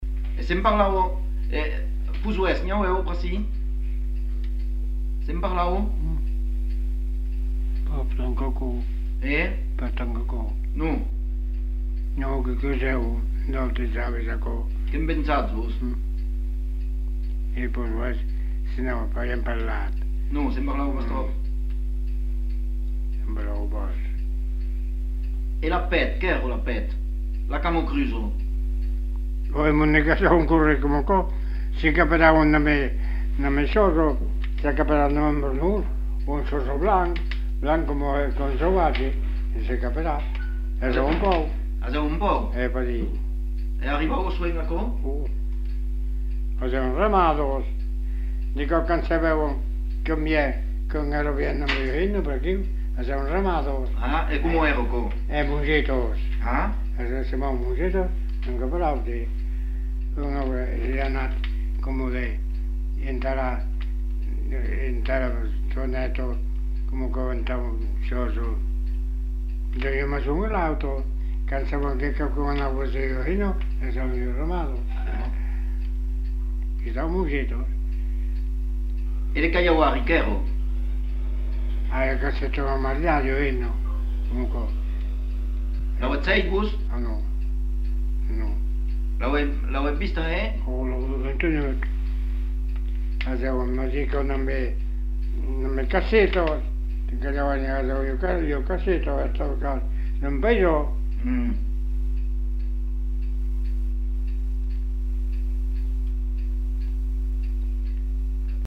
Lieu : Espaon
Genre : témoignage thématique